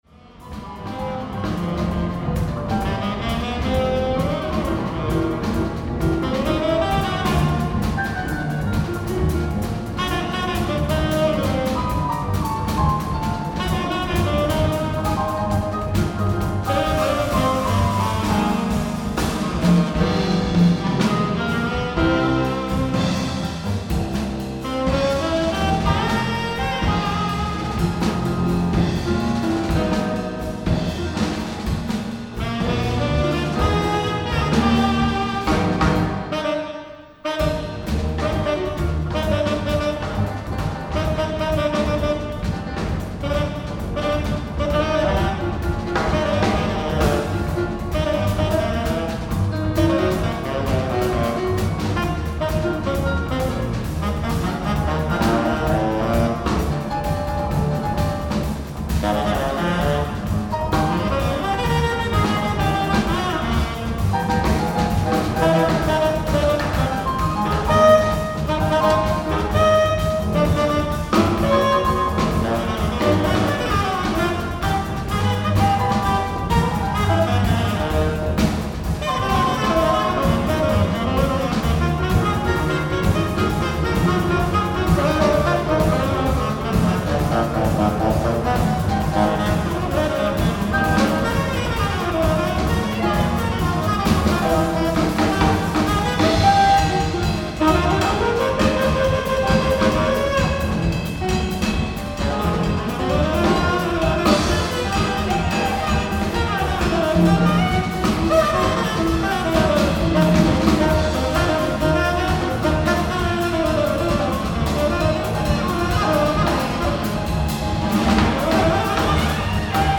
ライブ・アット・エイブリー・フィッシャー・ホール、ニューヨーク 06/26/2003
※試聴用に実際より音質を落としています。